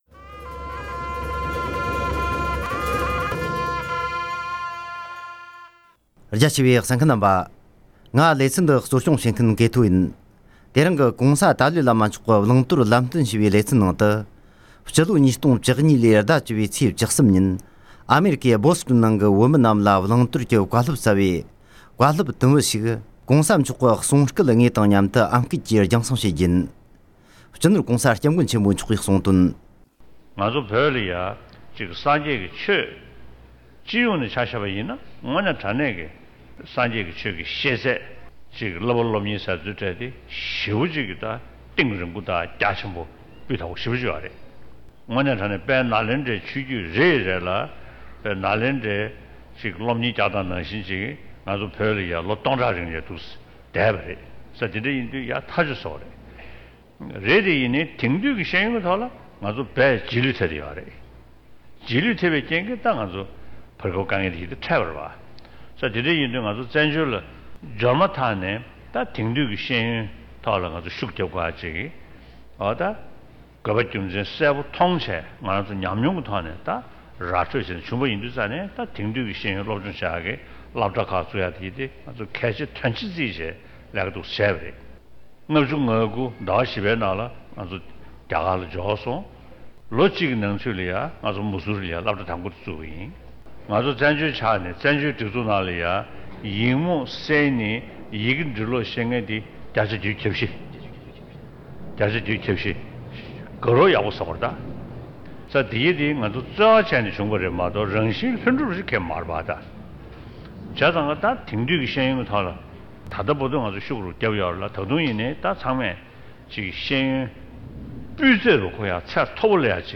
༸གོང་ས་མཆོག་གིས་དེང་དུས་ཀྱི་ཤེས་ཡོན་དང་མཉམ་དུ་སྲོལ་རྒྱུན་གྱི་ཤེས་ཡོན་ཡང་གལ་ཆེ་བའི་སྐོར་བཀའ་གནང་བ།